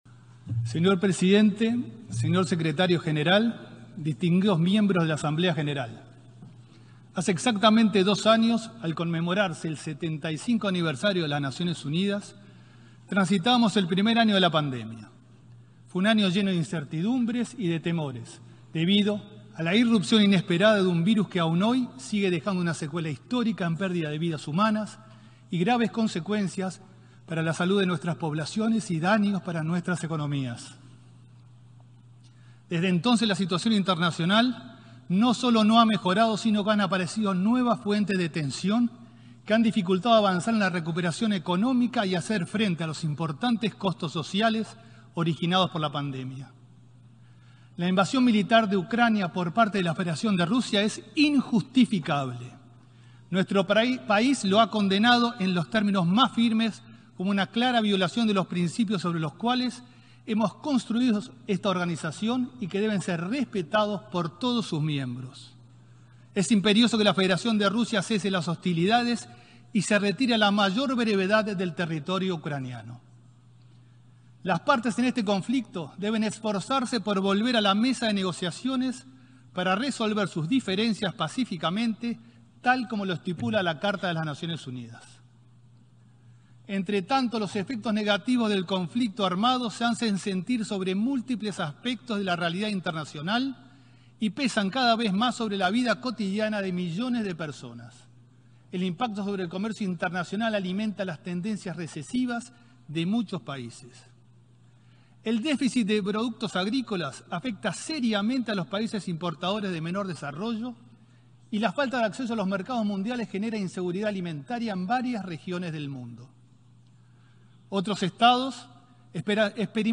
Disertación del canciller, Francisco Bustillo, en la 77.ª Asamblea General de las Naciones Unidas
Disertación del canciller, Francisco Bustillo, en la 77.ª Asamblea General de las Naciones Unidas 26/09/2022 Compartir Facebook X Copiar enlace WhatsApp LinkedIn El ministro de Relaciones Exteriores, Francisco Bustillo, disertó este lunes 26, en representación de Uruguay, en la 77.ª Asamblea General de las Naciones Unidas.